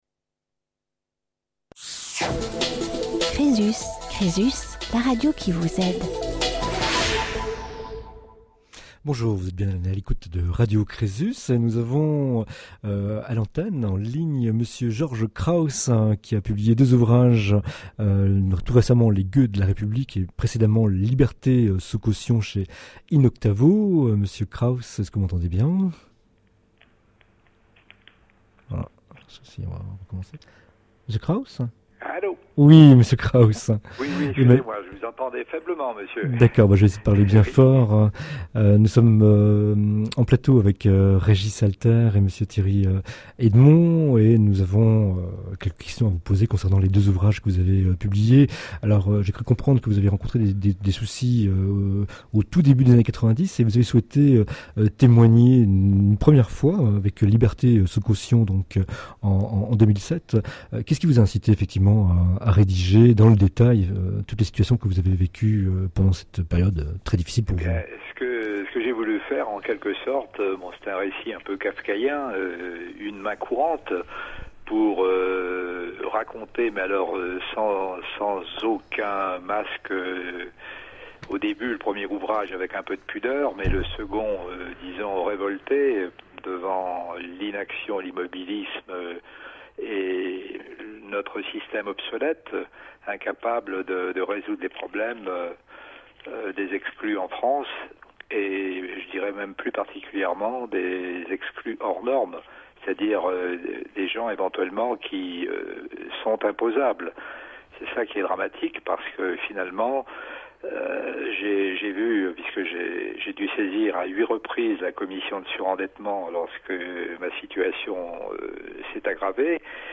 Les flashs info conso de CRESUS en partenariat avec la Chambre de Consommation d’Alsace.